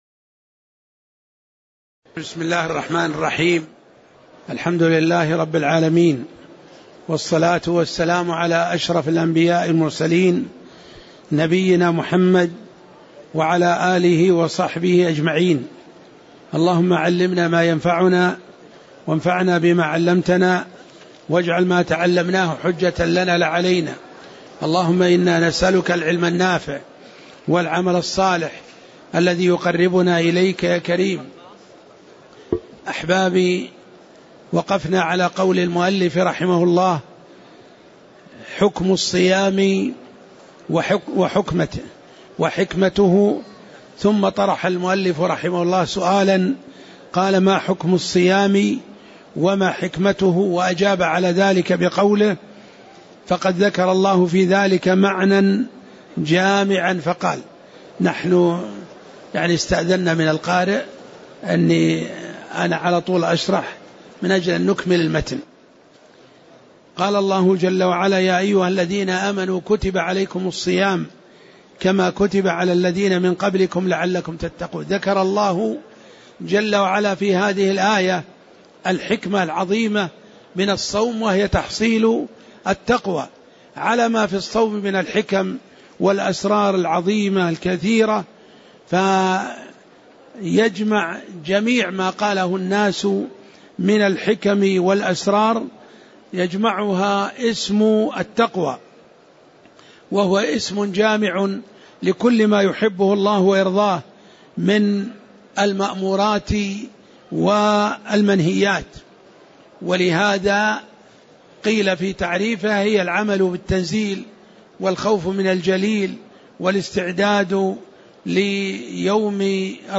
تاريخ النشر ١٧ شوال ١٤٣٨ هـ المكان: المسجد النبوي الشيخ